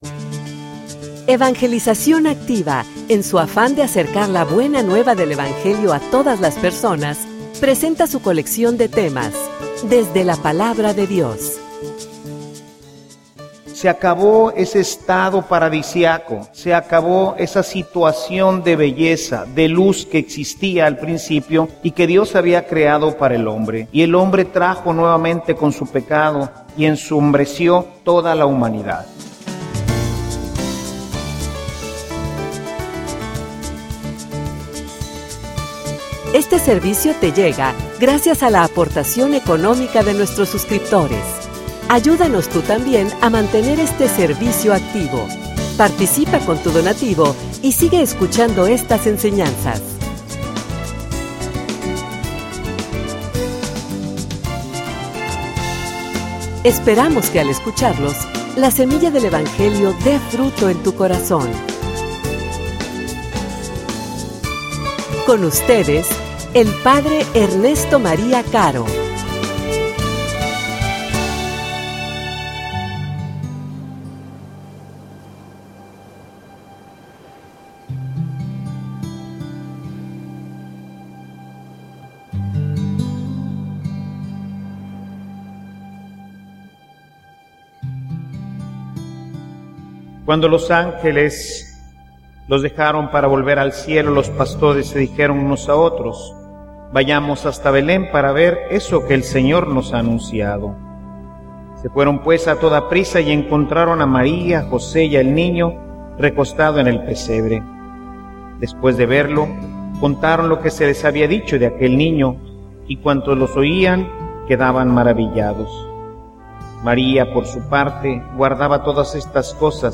homilia_Tu_eres_la_luz_del_mundo.mp3